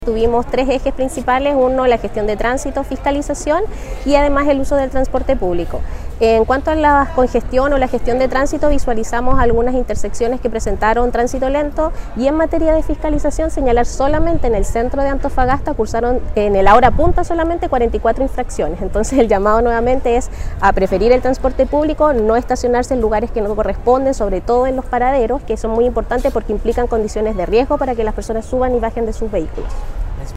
Más detalles entrega la Seremi de Transportes, Romina Vera: